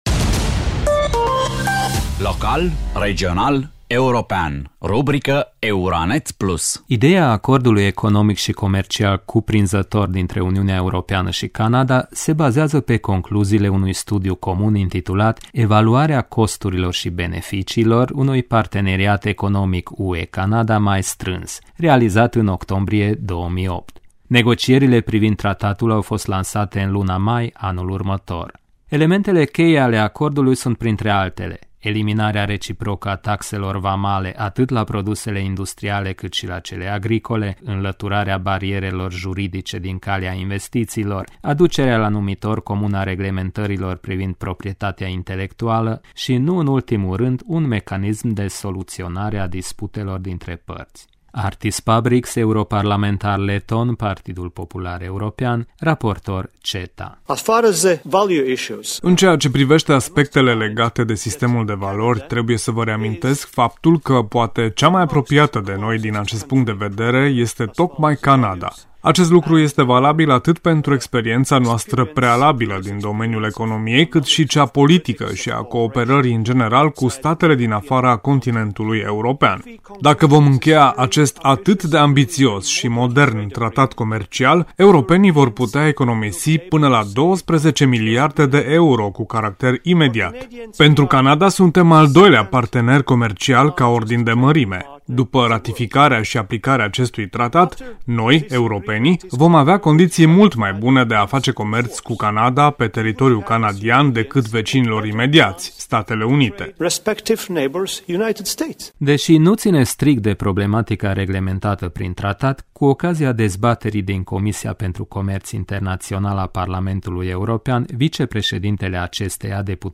Artis Pabriks, europarlamentar leton, Partidul Popular European, raportor CETA: